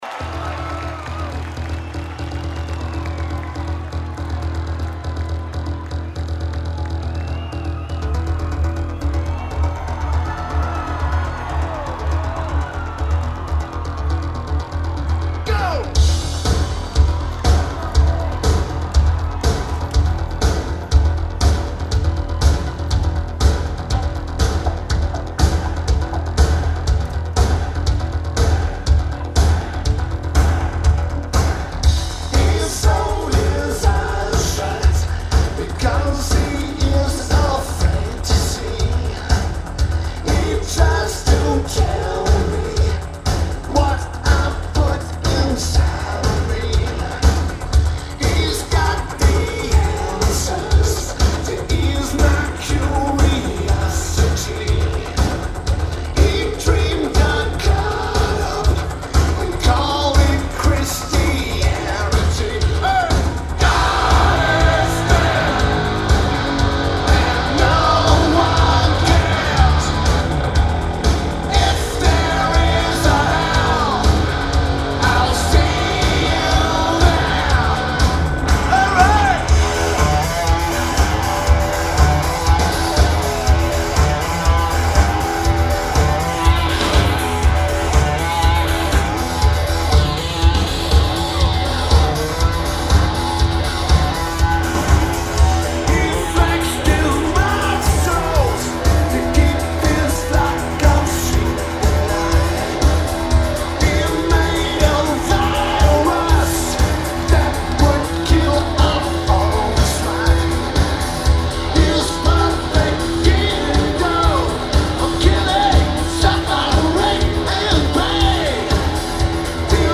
Independence Day Festival
This is one of the best sounding recordings of the tour.